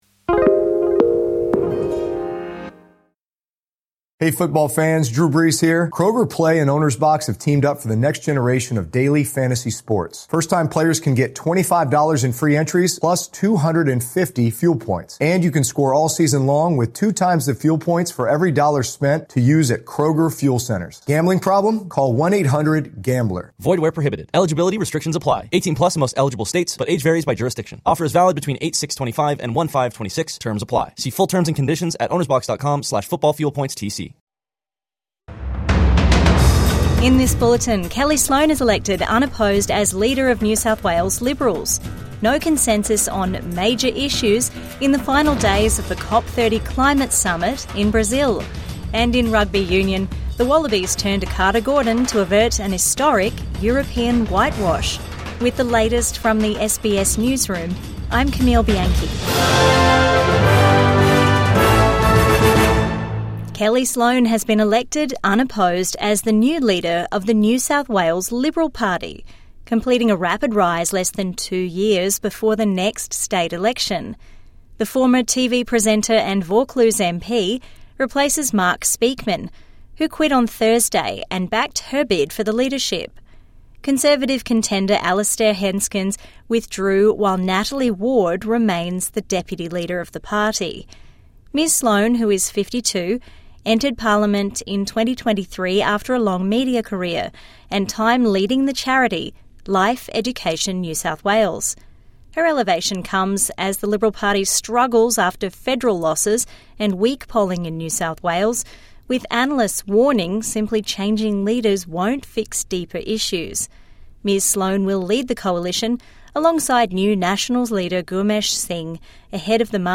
Kellie Sloane elected NSW Liberals leader | Midday News Bulletin 21 November 2025